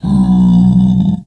Minecraft Version Minecraft Version latest Latest Release | Latest Snapshot latest / assets / minecraft / sounds / mob / zombified_piglin / zpig1.ogg Compare With Compare With Latest Release | Latest Snapshot